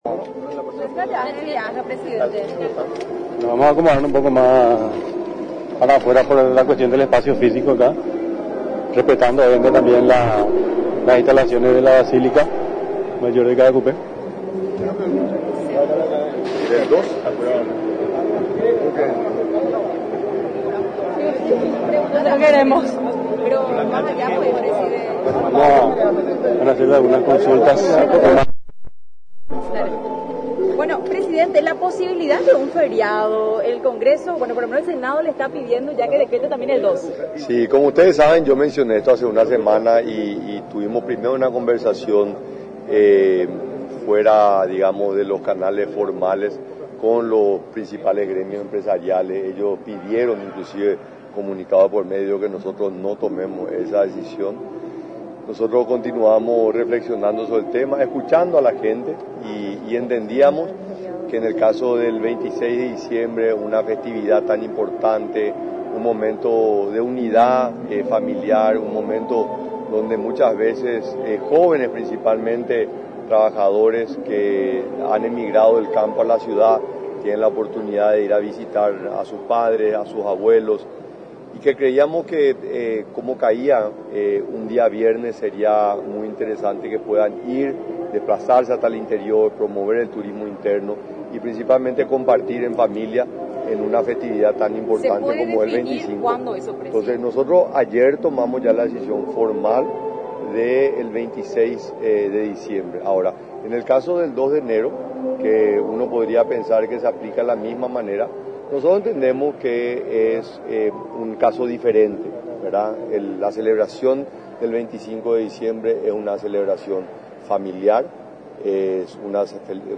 Explicó, durante una rueda de prensa en Caacupé, que evaluaron esta decisión con base a los pedidos de los gremios empresariales y del impacto que podría generar en la sociedad.